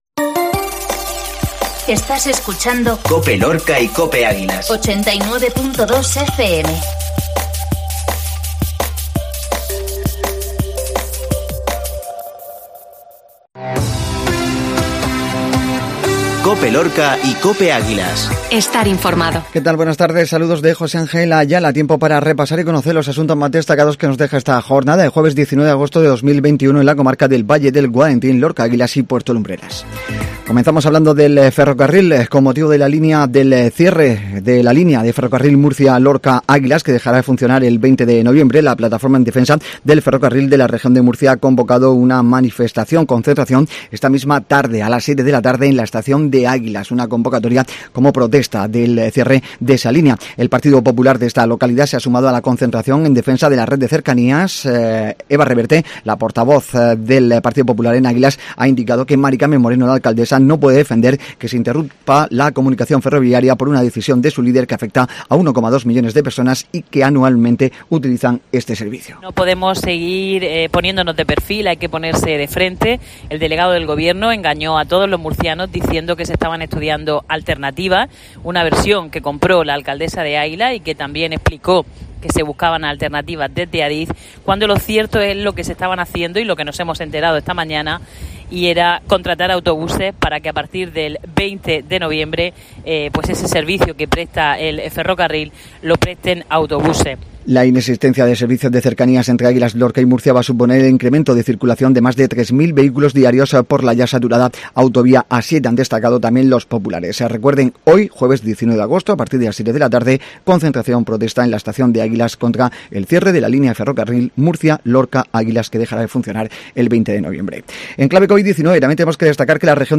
INFORMATIVO MEDIODÍA JUEVES